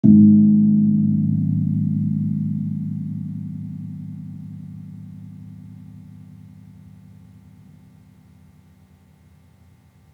Gamelan / Gong
Gong-G#2-p.wav